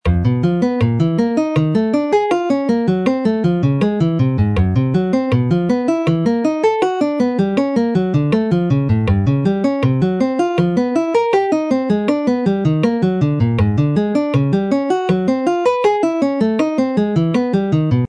We start off on the sixth string, first fret, then the fifth string second fret, then fourth string third fret, and finish off this section on the third string fourth fret.
The Spider exercise
dexterity-exercise-1-the-spider.mp3